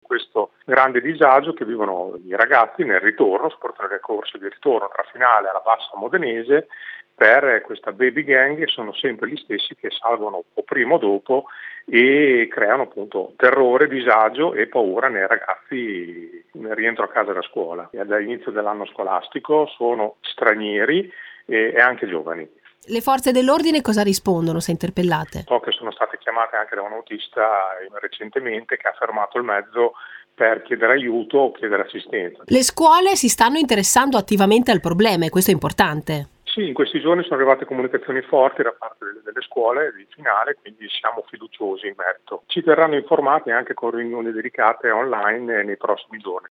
Sentiamo il padre di uno degli studenti…